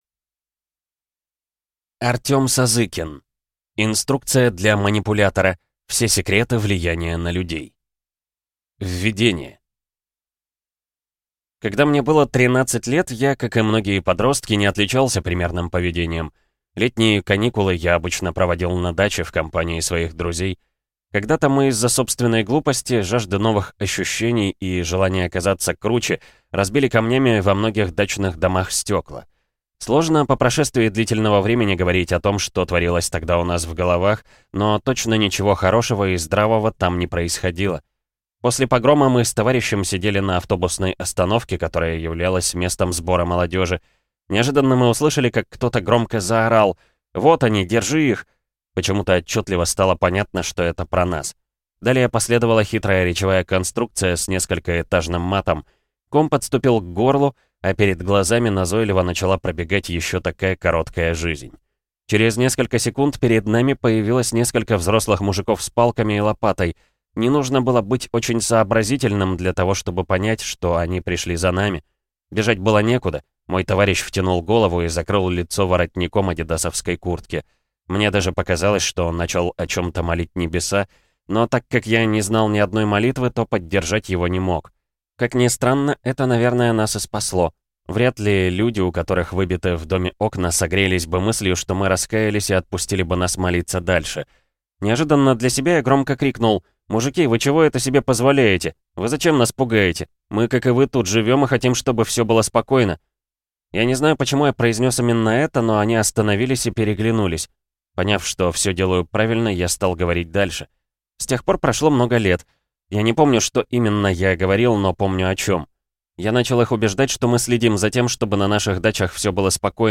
Аудиокнига Инструкция для манипулятора. Все секреты влияния на людей | Библиотека аудиокниг